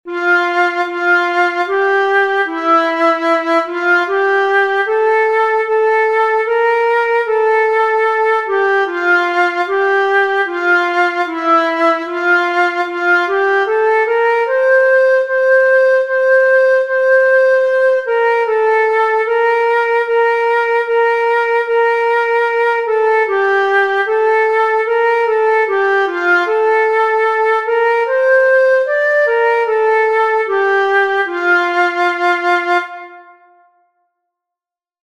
Free Sheet Music for Flute
Traditional
The flute’s clear, commanding tone can beautifully articulate the anthem’s powerful melody, making it an ideal choice for patriotic events, state functions, or performances that aim to honor national traditions and history.